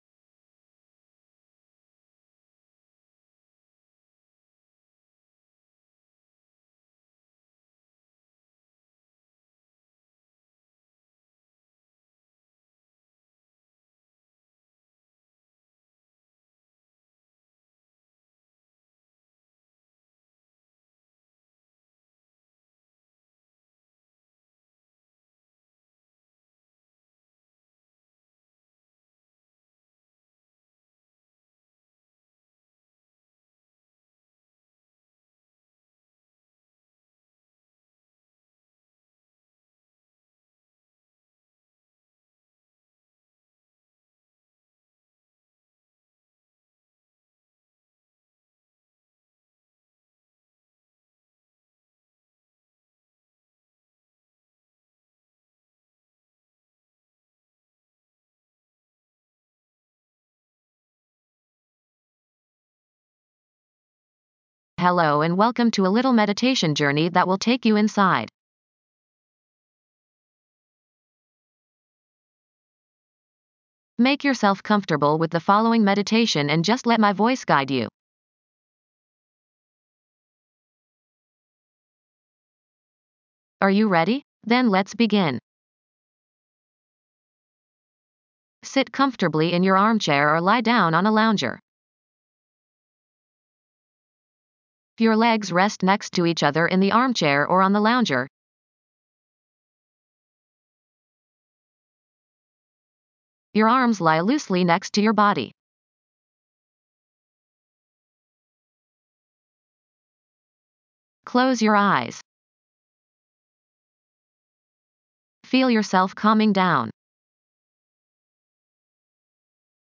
Hold your concentration on this / these places while you let the music play over you.